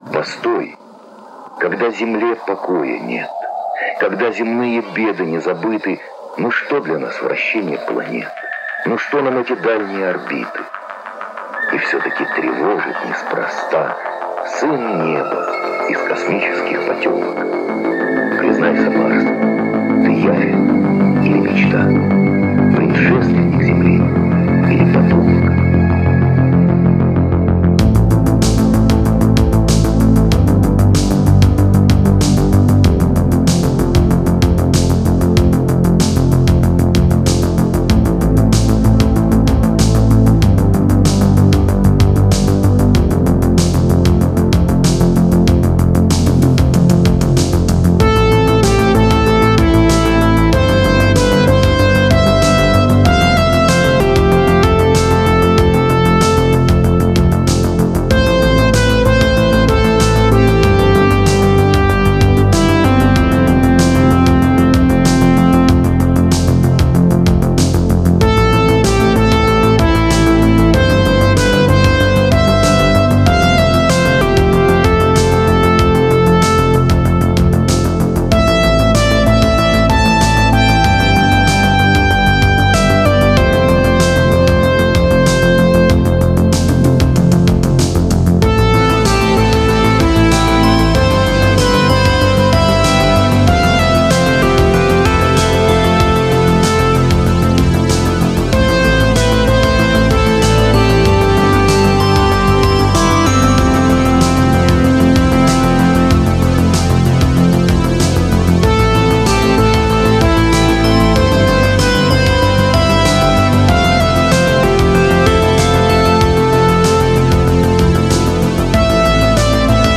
Жанр: Synthwave